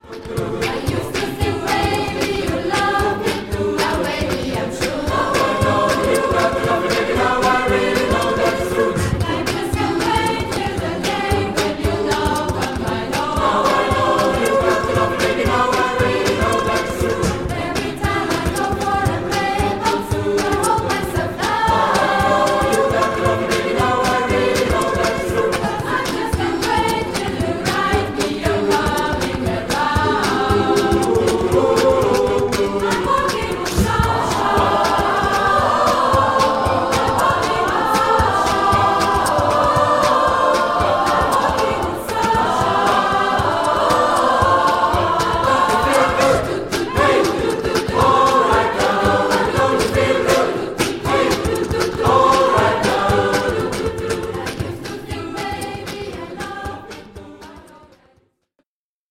a Cappella
SMATB